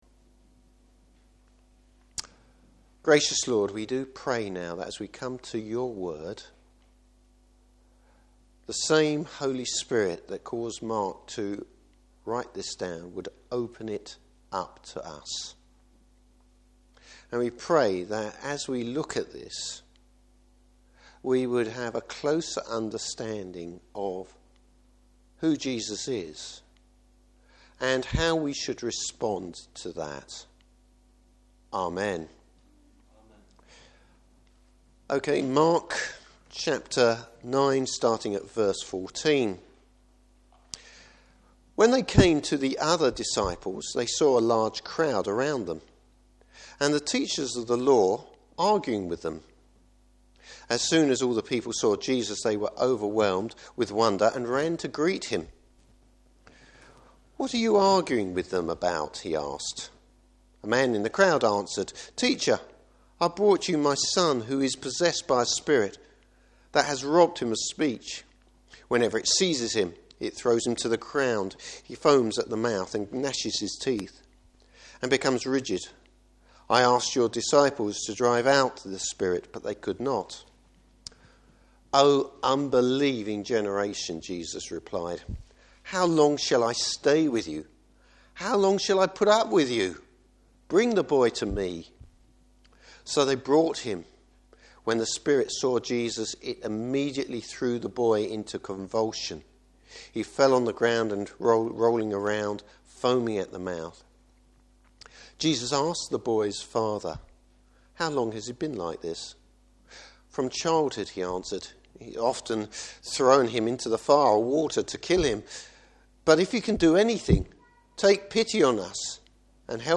Service Type: Morning Service Faith is manifested through prayer.